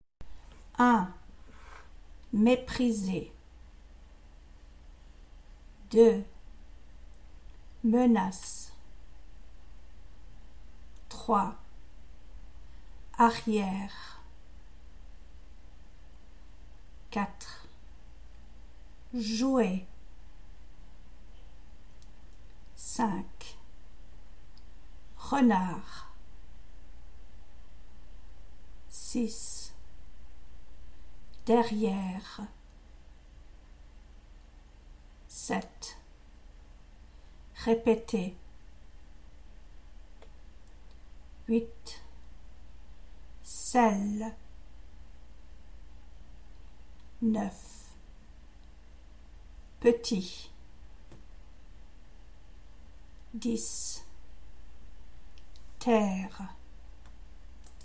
Indiquez si vous entendez le son 'È', 'É' ou 'E'.